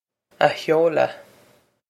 Pronunciation for how to say
a hyo-la
This is an approximate phonetic pronunciation of the phrase.